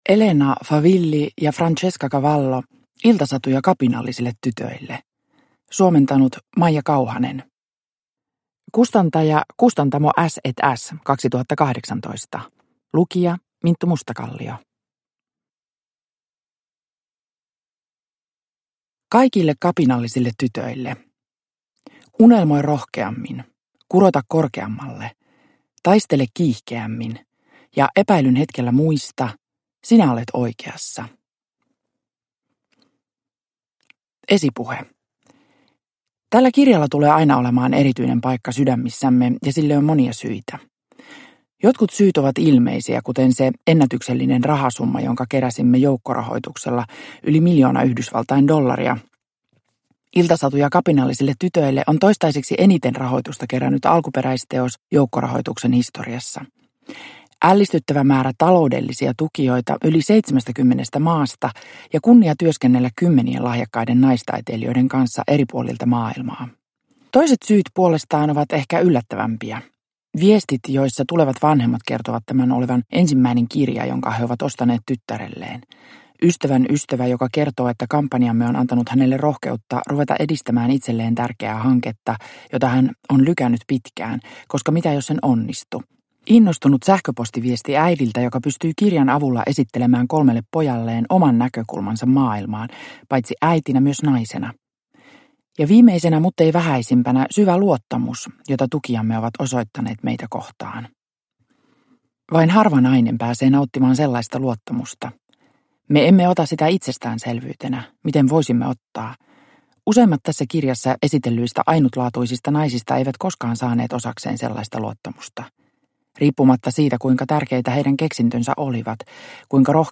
Iltasatuja kapinallisille tytöille – Ljudbok – Laddas ner